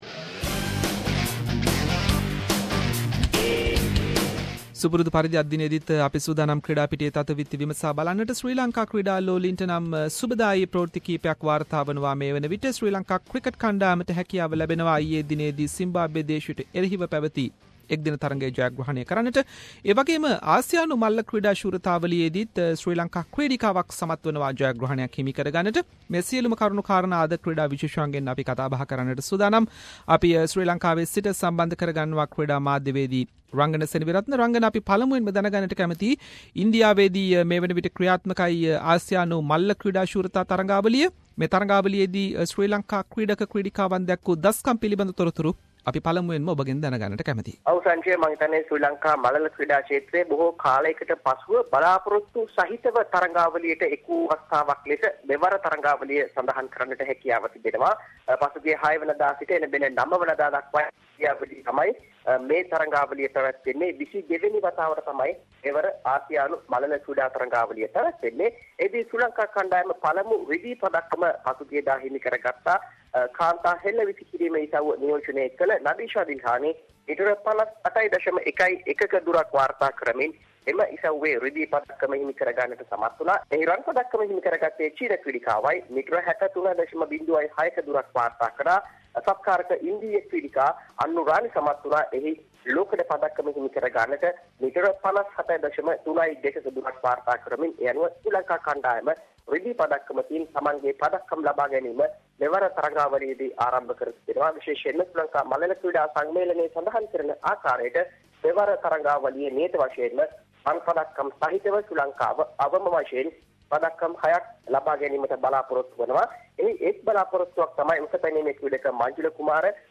In this weeks SBS Sinhalese sports wrap…. Sri Lankan athlete Nadeesha Dilahani Wins Silver in Asian athletics Championship for Javeline throw, Latest from Sri Lanka Vs Zimbabwe ODI series, Cricket Australia - A tour officially called off over ongoing pay dispute, Latest from womens cricket world cup and international sports news.